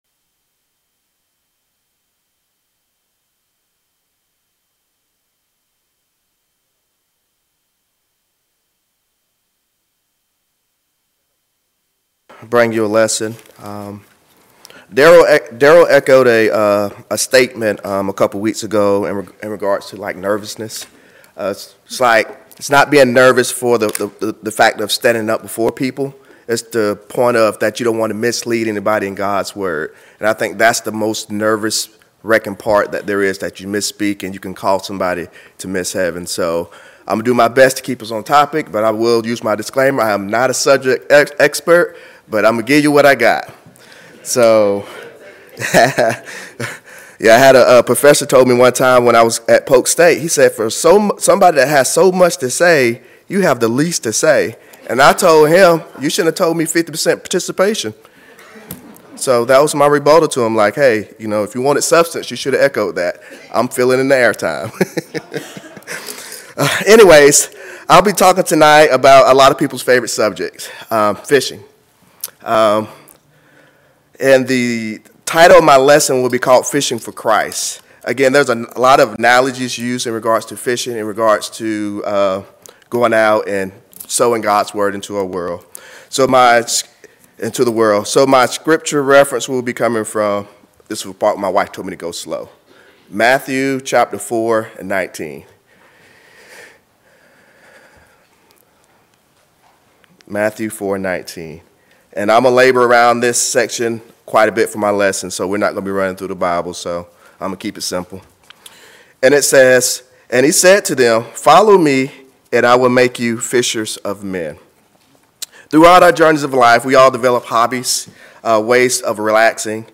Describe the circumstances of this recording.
Sun PM Worship